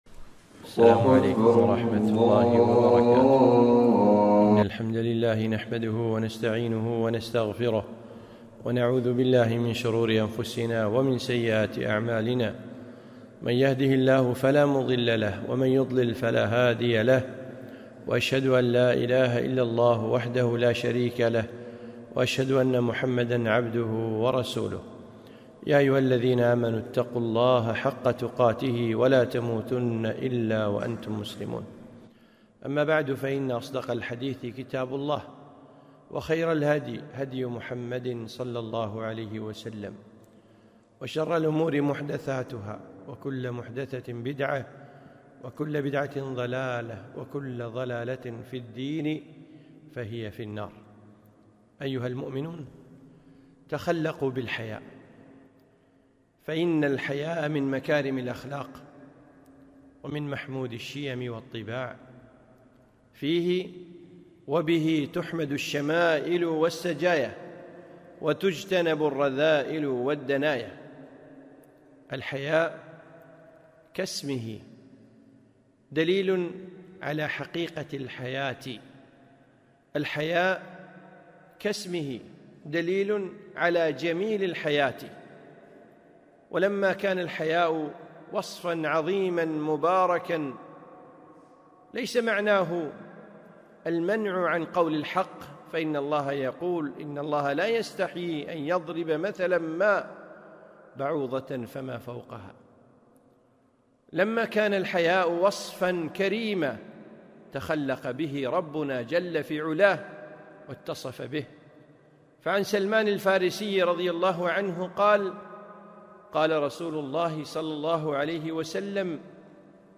خطبة - تخلق بالحياء